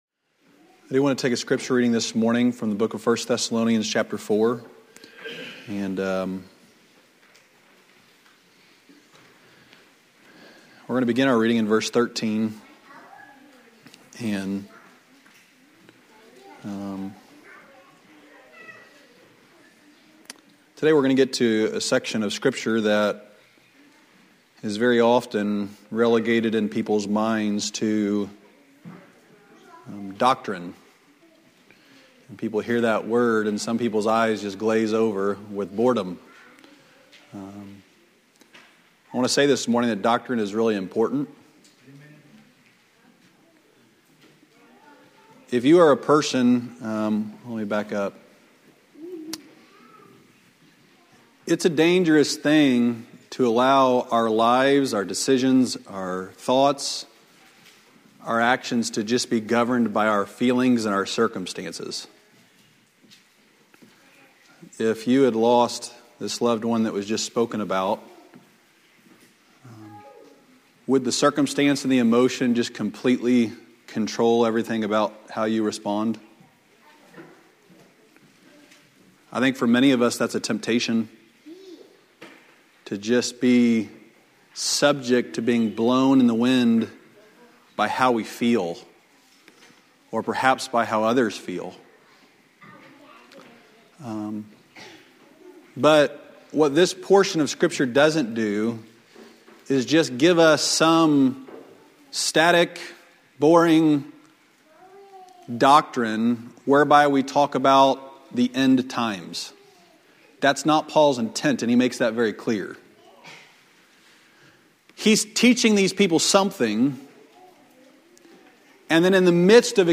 From Series: "Sunday Morning Messages"